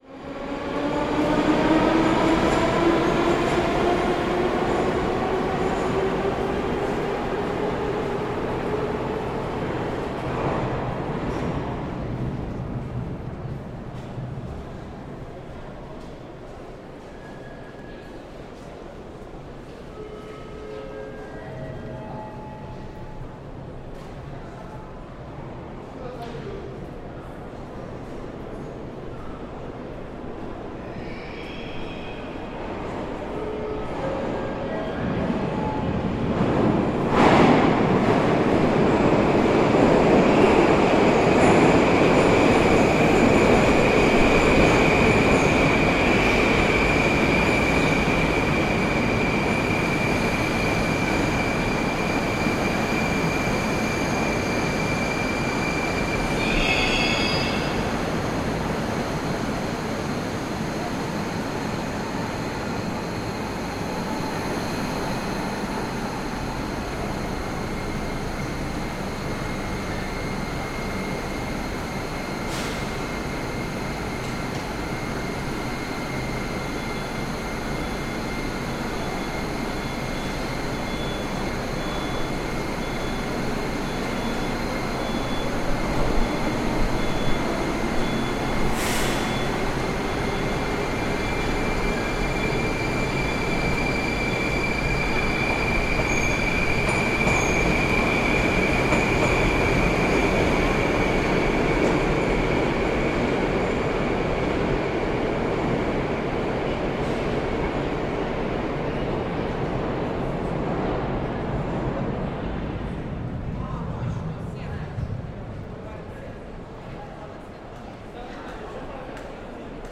Station ambience on the platform at one of the most famous stations on the Moscow metro, Ploshchad Revolyutsii (Revolution Square) as trains arrive and leave.